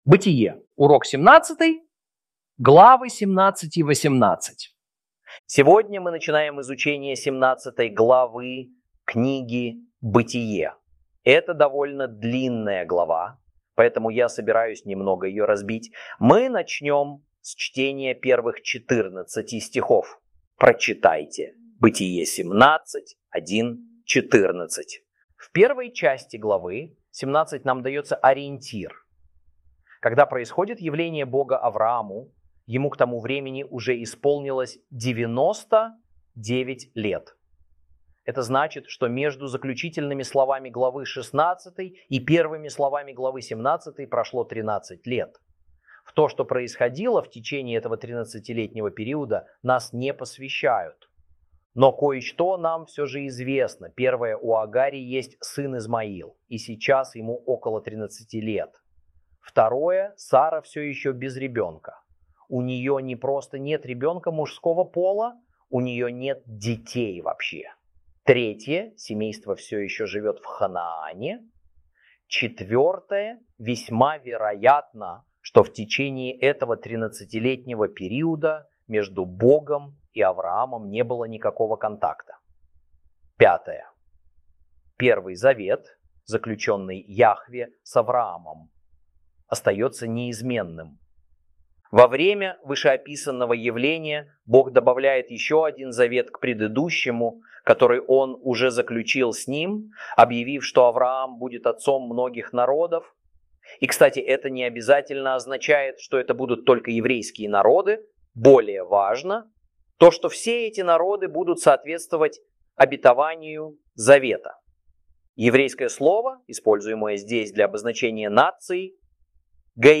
Урок 17 - Бытие́ 17 & 18 - Torah Class